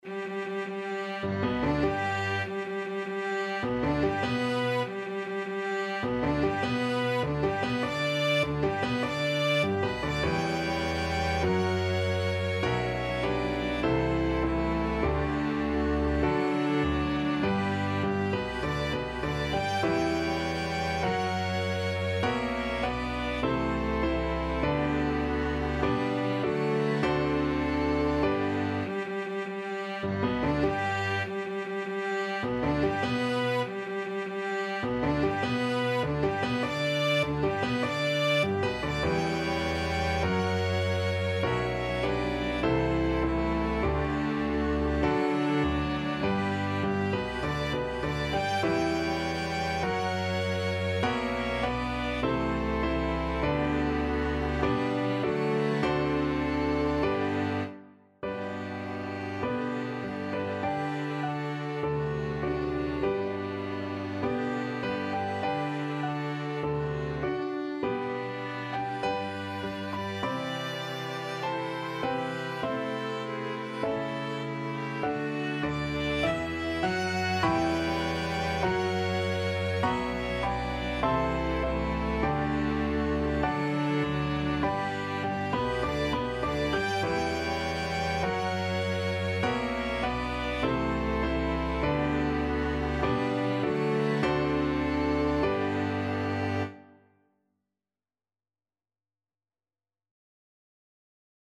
Violin 1Violin 2ViolaCelloDouble BassPiano
Andante maestoso =100
4/4 (View more 4/4 Music)
Classical (View more Classical Piano and Ensemble Music)